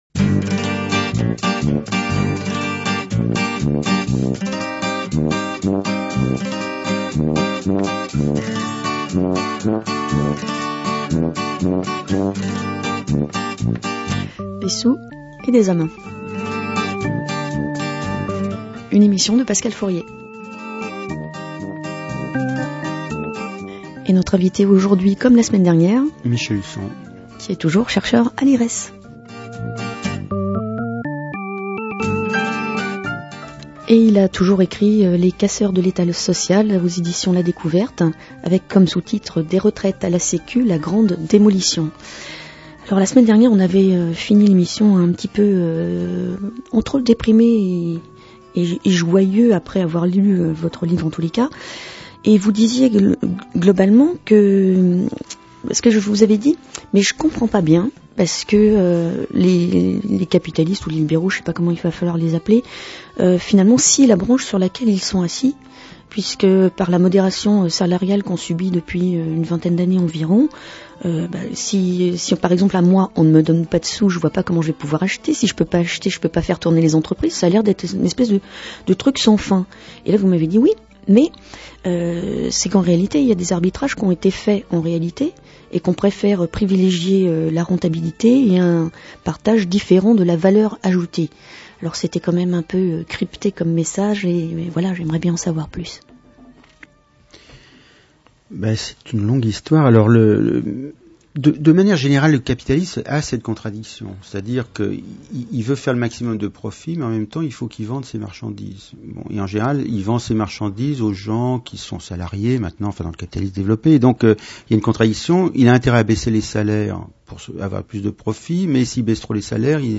L’émission radiophonique (au format mp3)